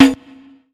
TC2 Perc9.wav